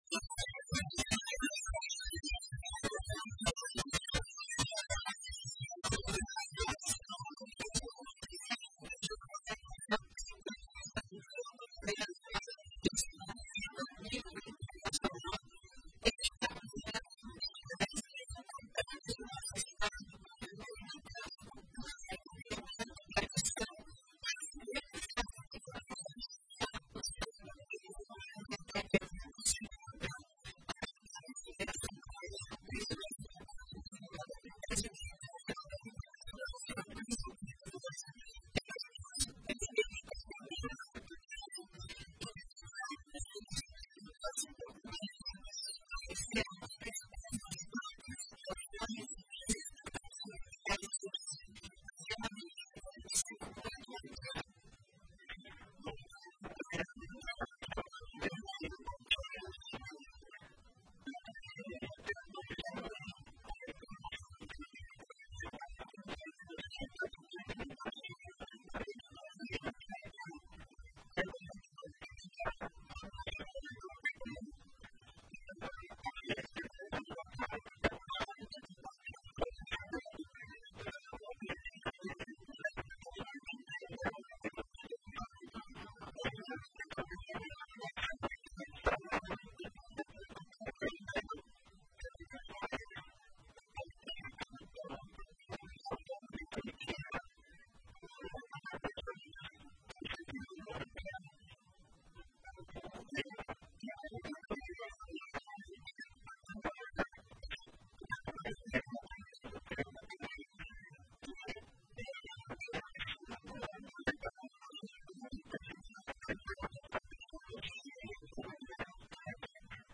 Também mencionou as inúmeras atrações artísticas, culturais e gastronômicas preparadas para essa edição especial. A entrevista completa está disponível abaixo: